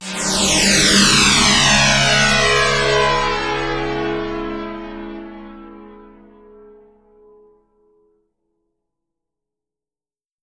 Index of /90_sSampleCDs/E-MU Producer Series Vol. 4 – Denny Jaeger Private/Effects/Piano FX